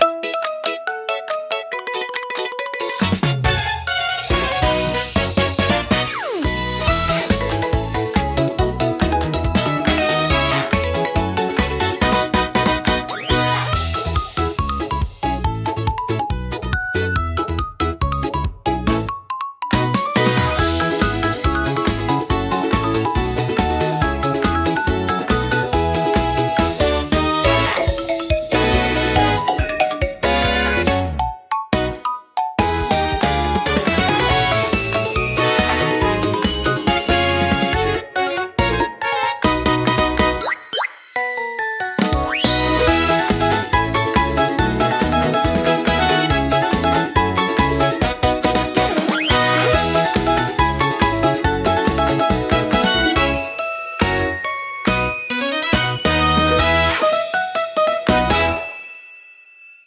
アクションは、明るくコミカルなシンセポップを特徴とするオリジナルジャンルです。
軽快なリズムと親しみやすいメロディーが、楽しく活発な雰囲気を作り出します。